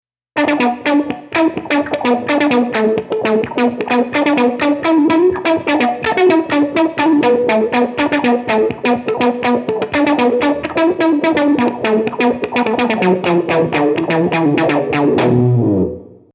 Compact ten-slider layout lets the sonically adventurous guitarist dial in a virtually limitless array of analog synthesizer sounds: from percussive stabs to backwards-sounding bowed effects.
Percussive Synth
Micro-SynthAnalog-Guitar-Microsynth-Percussive-Synth.mp3